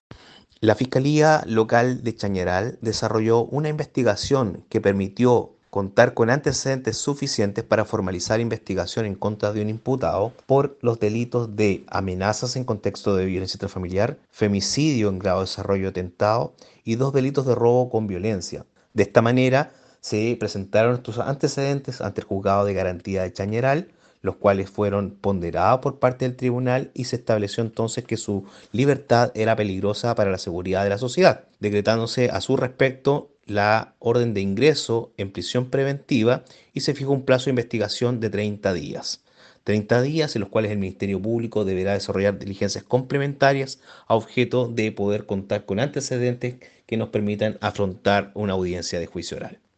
AUDIO-FISCAL-MARCO-ARENAS.mp3